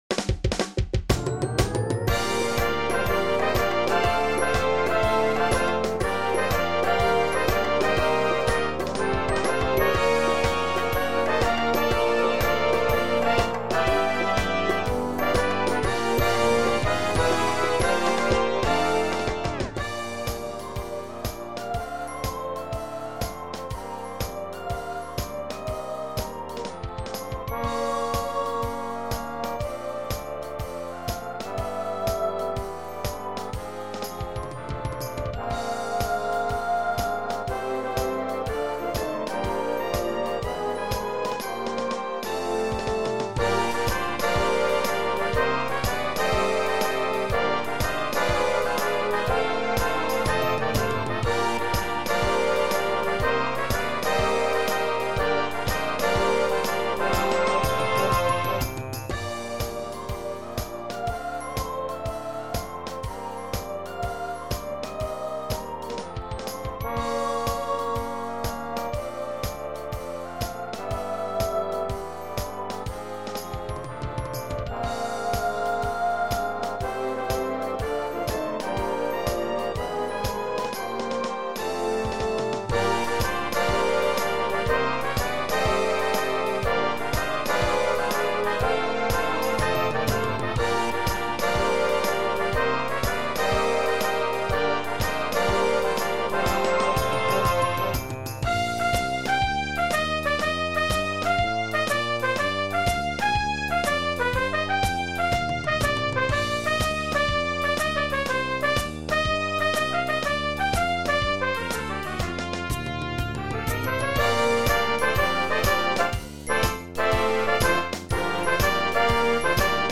117B1v  Brass Band $25.00 **
(computer generated sound sample)